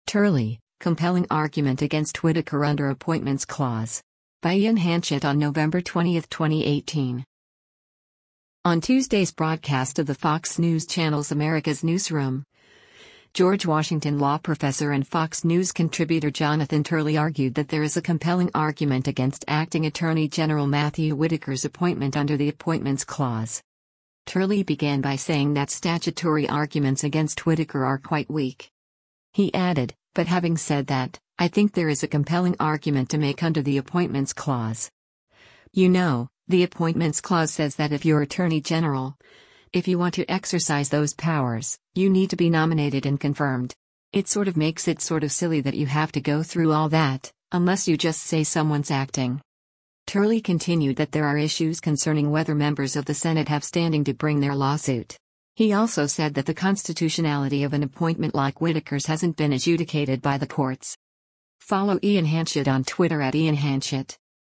On Tuesday’s broadcast of the Fox News Channel’s “America’s Newsroom,” George Washington Law Professor and Fox News Contributor Jonathan Turley argued that there is “a compelling argument” against Acting Attorney General Matthew Whitaker’s appointment under the Appointments Clause.